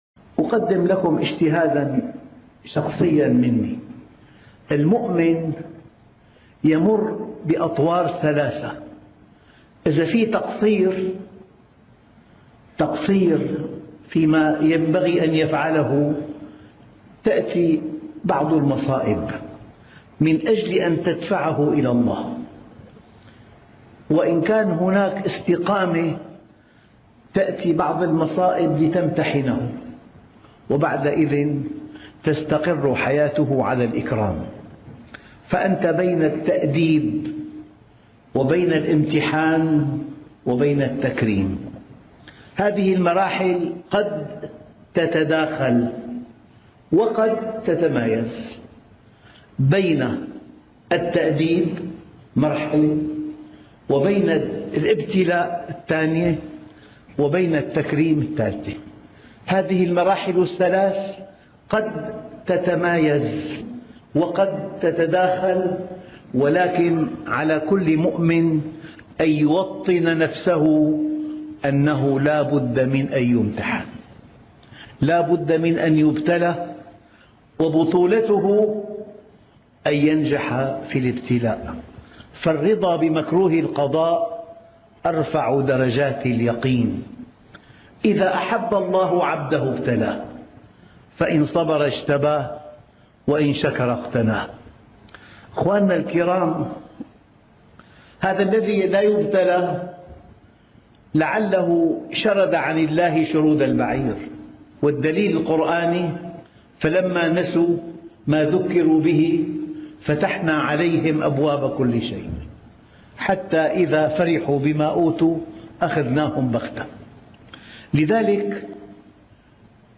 دروس تهز قلبك لماذا لا مفر من الابتلاء؟ - الشيخ محمد راتب النابلسي